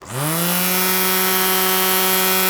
STRIMMER_On_mono.wav